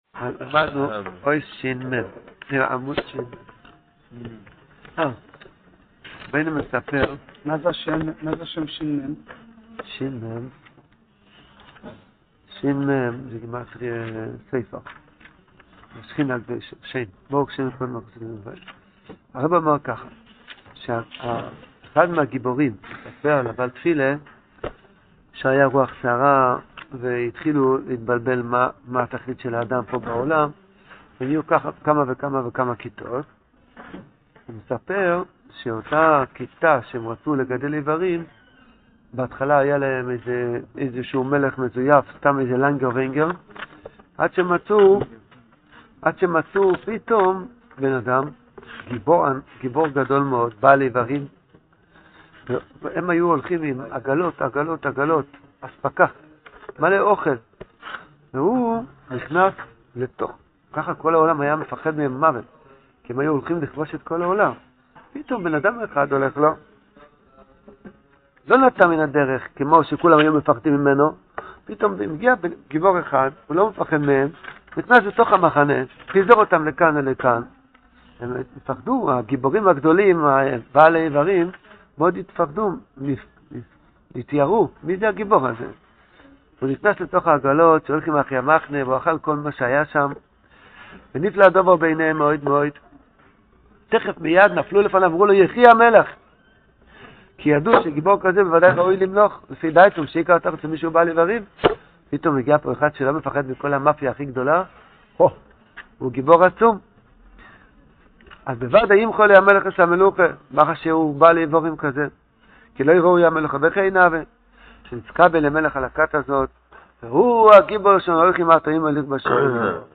This shiur is given daily after shachris and is going through each of the stories in sipurei maasios in depth. The audio quality gets better after episode 26.